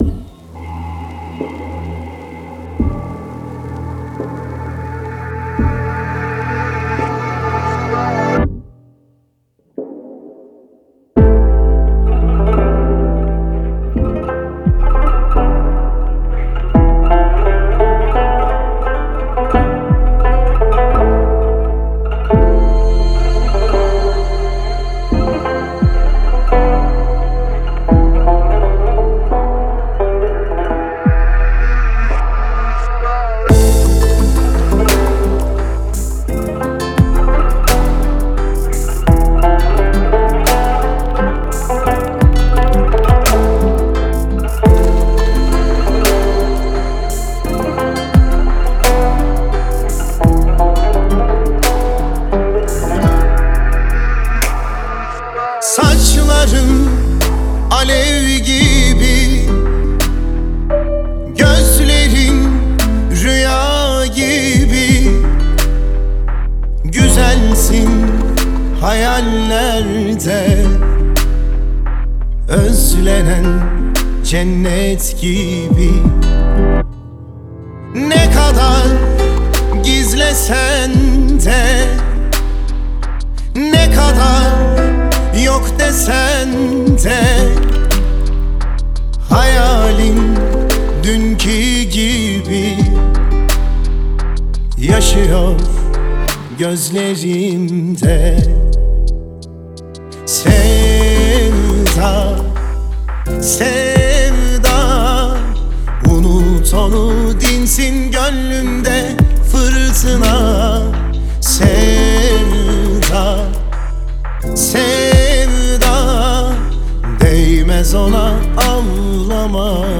آهنگ ترکیه ای آهنگ غمگین ترکیه ای